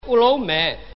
Avvertibilissime e chiarissimamente denotanti il parlato genovese genuino.
Come possibilità di realizzazione di dittonghi, può verificarsi, ad esempio, l'incontro di “a” od “e” finali di parola con “u” iniziale.